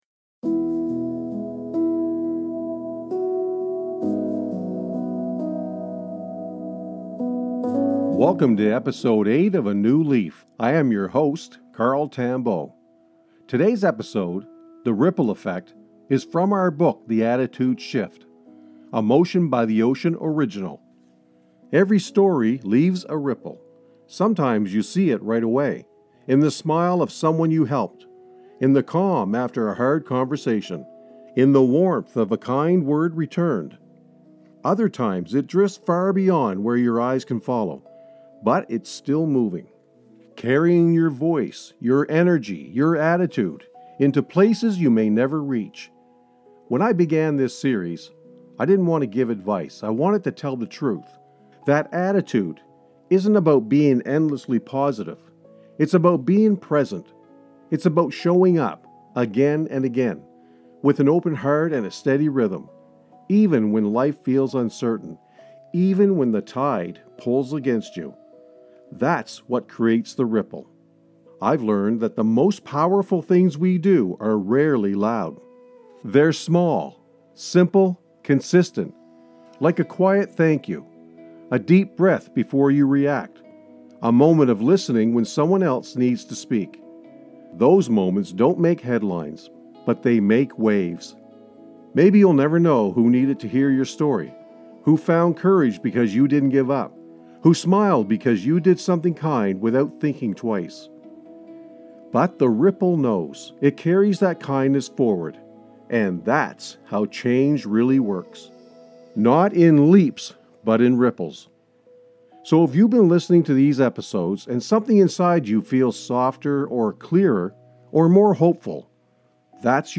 Through ocean-inspired storytelling, this episode reminds us that our attitude doesn’t just shape our own day — it shapes the days of those around us.